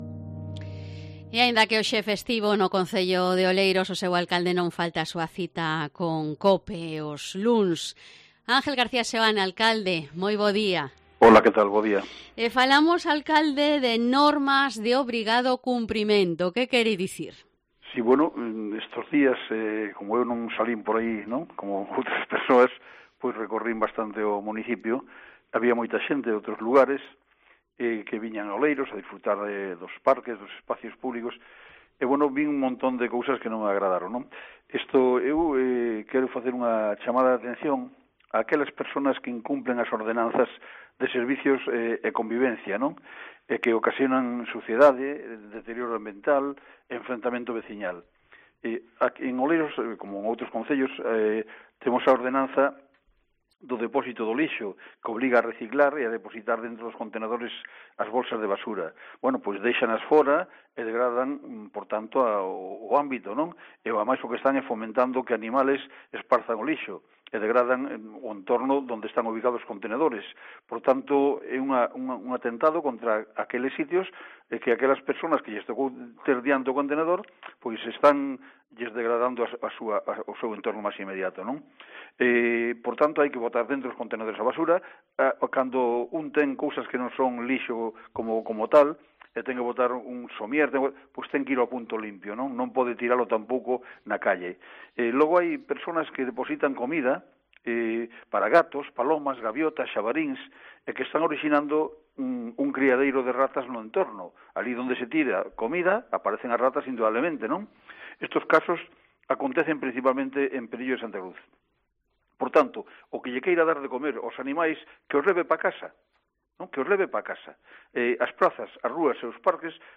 EN COPE ENTREVISTAMOS A ANGEL GARCÍA SEOANE, ALCALDE DE OLEIROS